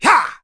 Zafir-Vox_Attack1.wav